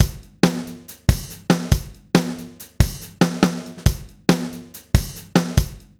drumloop.wav